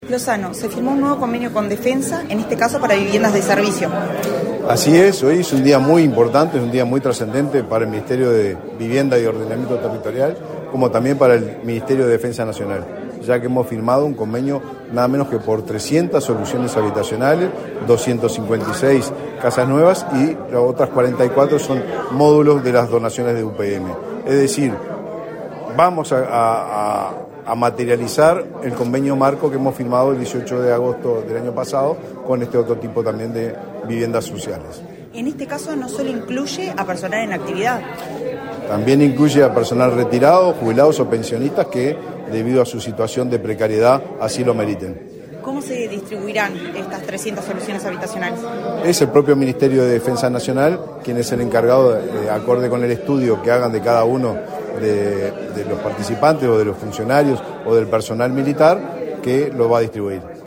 Declaraciones a la prensa del ministro de Vivienda, Raúl Lozano
Tras participar en la firma de convenio entre los ministerios de Vivienda y Ordenamiento Territorial y el de Defensa Nacional, este 9 de diciembre,